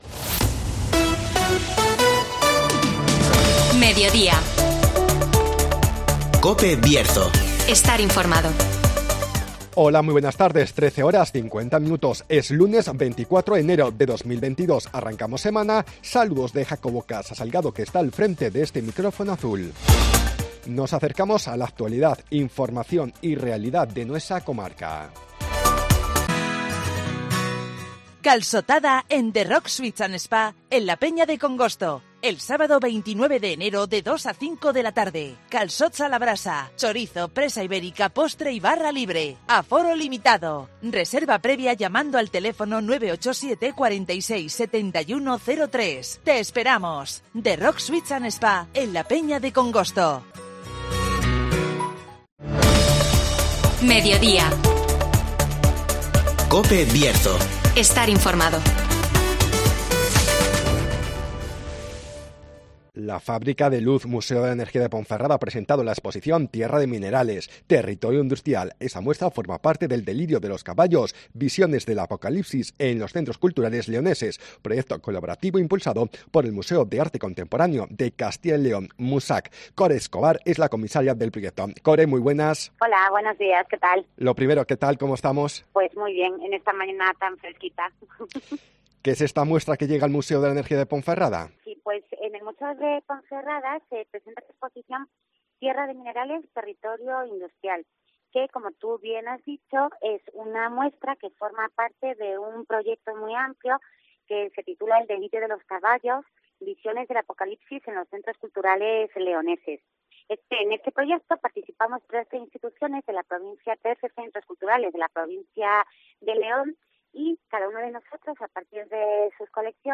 ACTUALIDAD